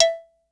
Perc_09.wav